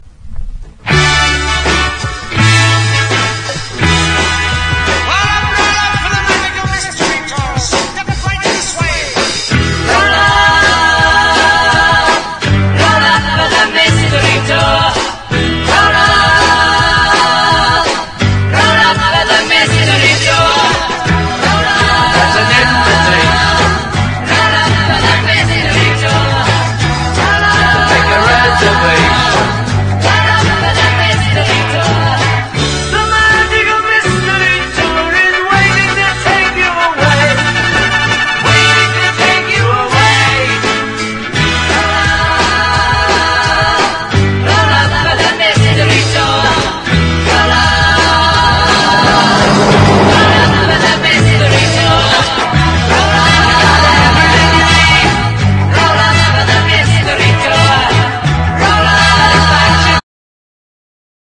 ROCK / 60'S